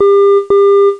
Marker Beacons